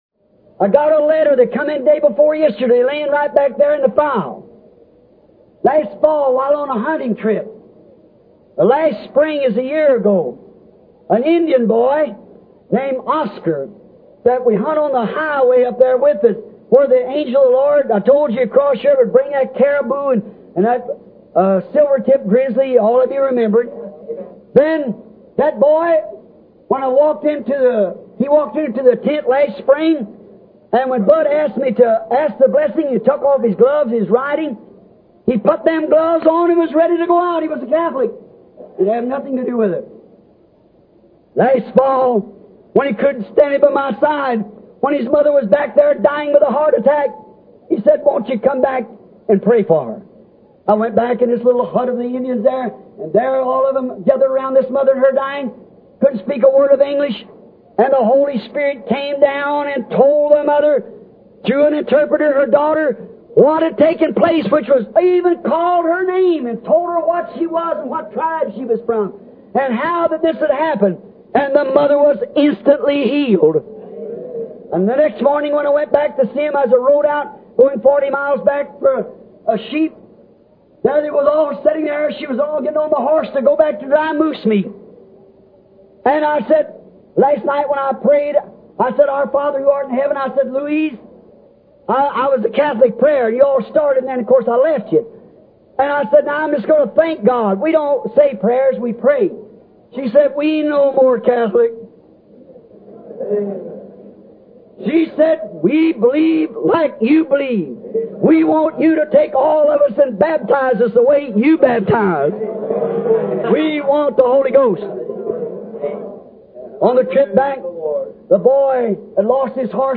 MP3 Audio Excerpt